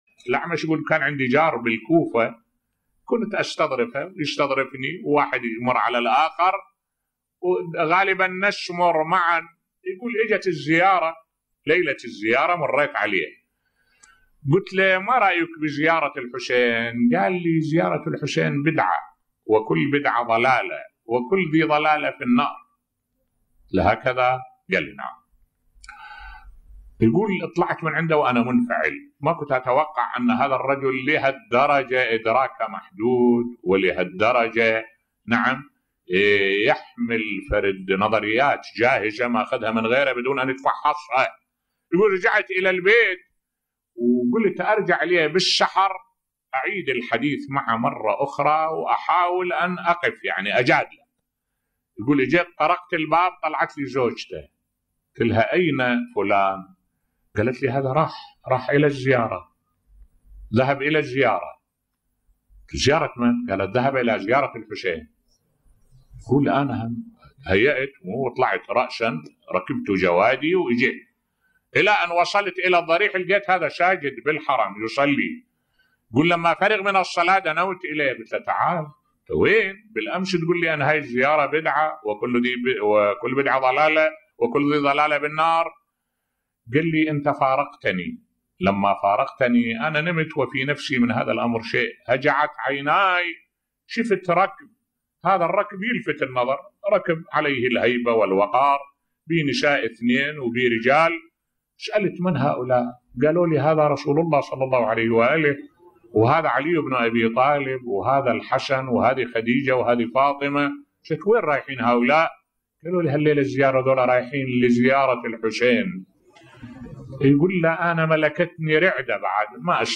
ملف صوتی الهدف من زيارة الحسين عليه السلام بصوت الشيخ الدكتور أحمد الوائلي